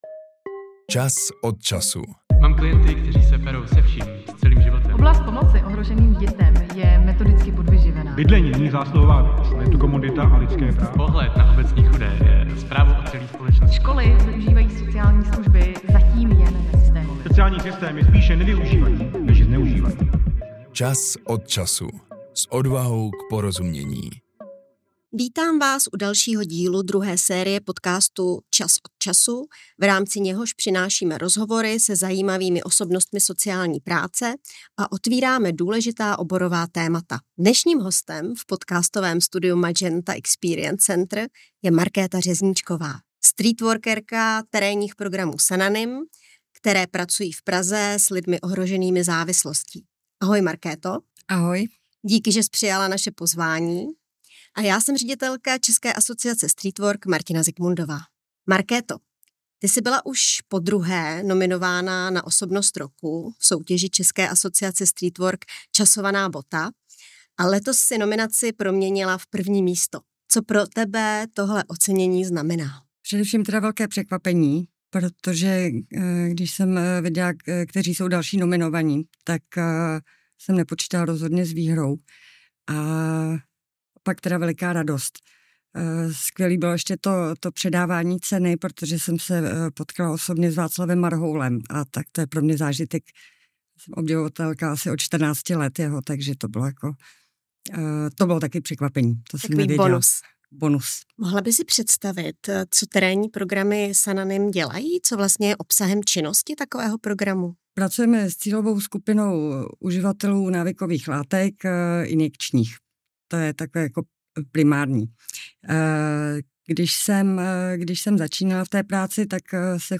O tom, jaké jsou potřeby uživatelů injekčně aplikovaných návykových látek, co obnáší zapojování těchto klientů ve službách a o přinášení inovací do prostředí nízkoprahových terénních služeb se dozvíte v následujících 40 minutách. Rozhovor